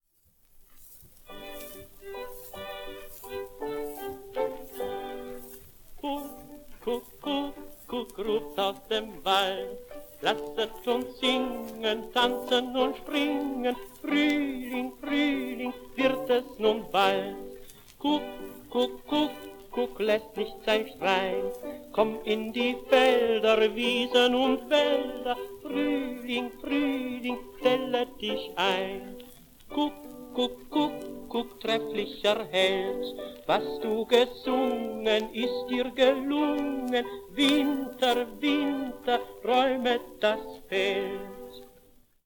Volkslied